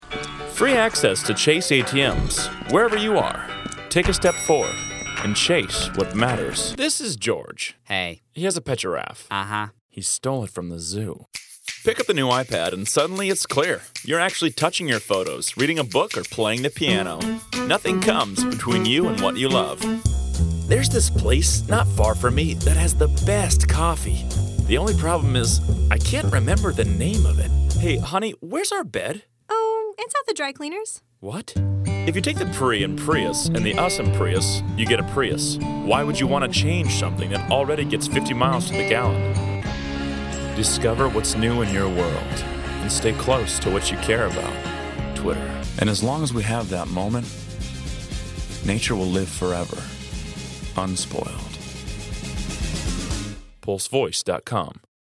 A deep resonant tone that is warm and engaging.